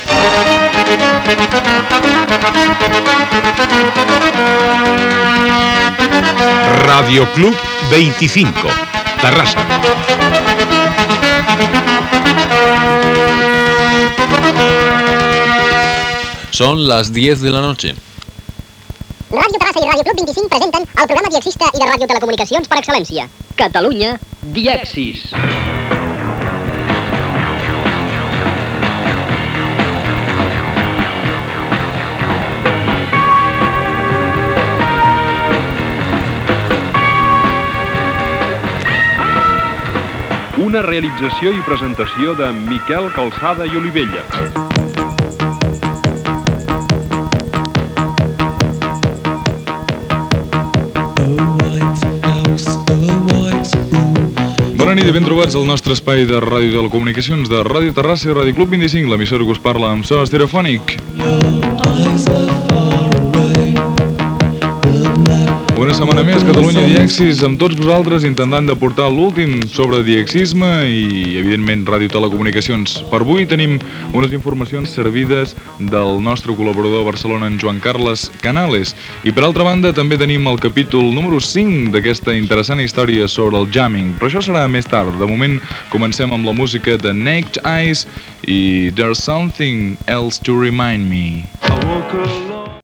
Sintonia del programa i presentació inicial.
Divulgació
FM